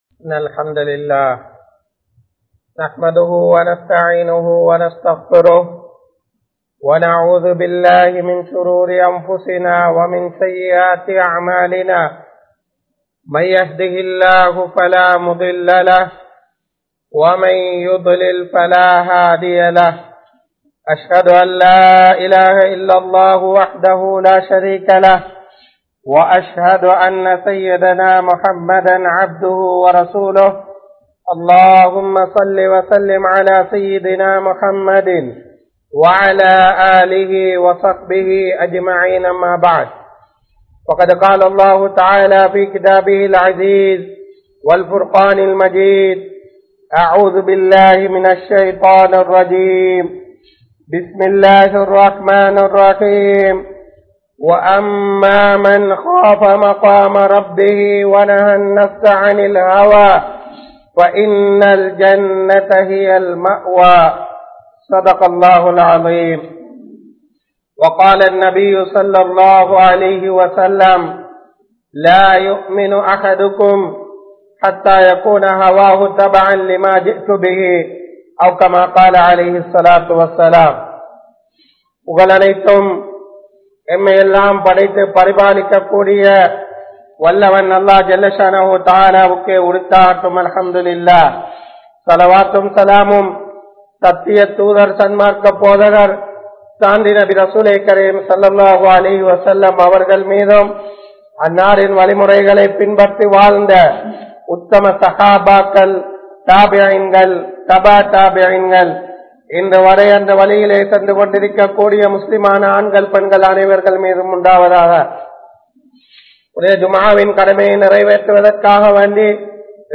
Allah`vai Maranthu Vidaatheerhal (அல்லாஹ்வை மறந்து விடாதீர்கள்) | Audio Bayans | All Ceylon Muslim Youth Community | Addalaichenai
Masjidun Noor Jumua Masjidh